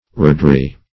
Roughdry \Rough"dry`\